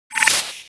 marine_points_received.wav